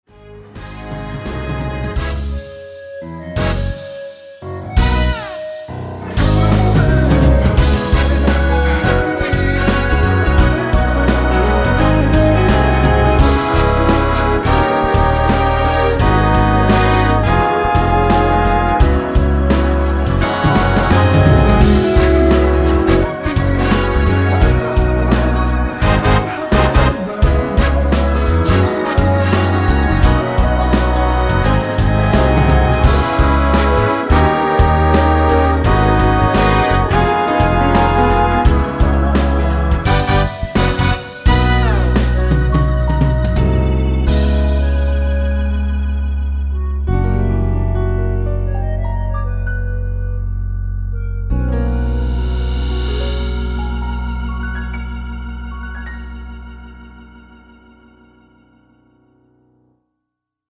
2분56초 부터 10초 정도 보컬 더블링 된 부분은 없습니다.(미리듣기 확인)
원키에서(-3)내린 멜로디와 코러스 포함된 MR입니다.
Bb
앞부분30초, 뒷부분30초씩 편집해서 올려 드리고 있습니다.
중간에 음이 끈어지고 다시 나오는 이유는